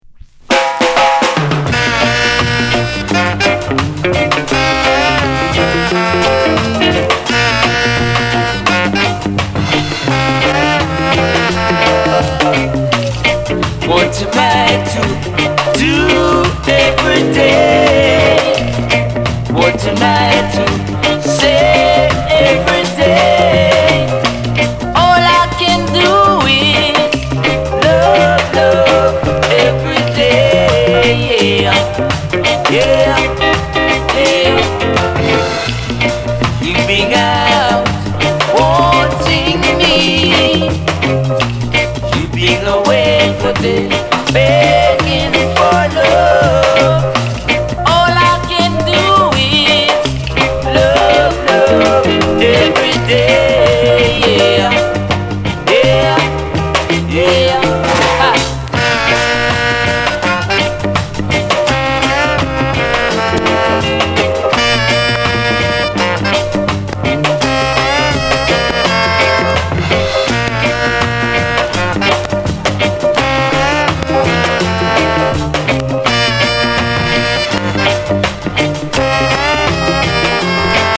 Genre: Rocksteady